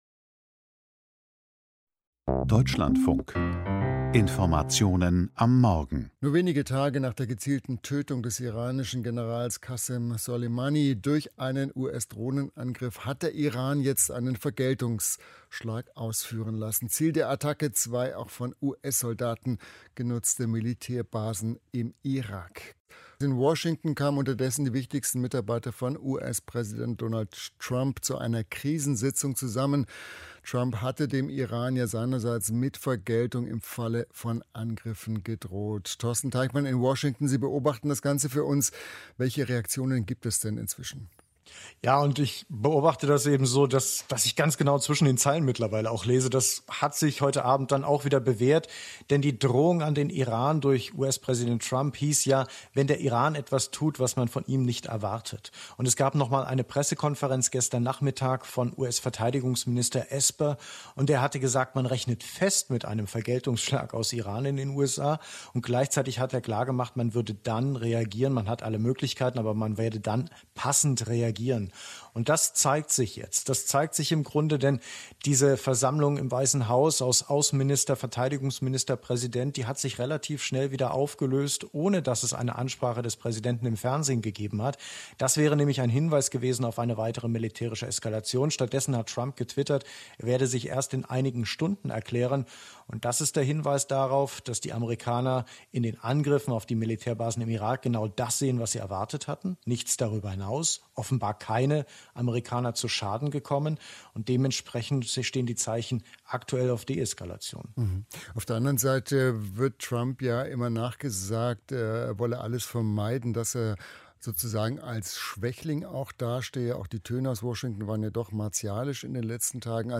Quelle: Alle Audiodateien sind Berichte bzw. ein Interview des Deutschlandfunks, welche im Morgenmagazin vom 8.1.2020 gesendet wurden.